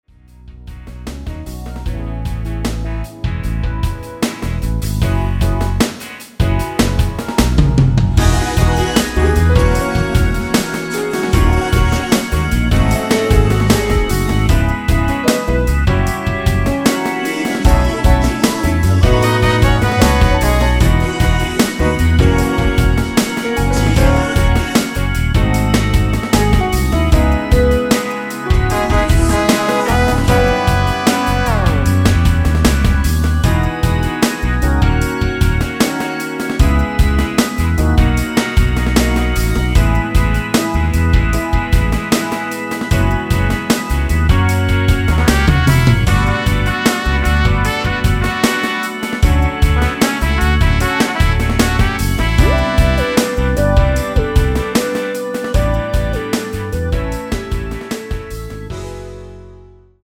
(-2) 내린코러스 포함된 MR 입니다.(미리듣기 참조)
앞부분30초, 뒷부분30초씩 편집해서 올려 드리고 있습니다.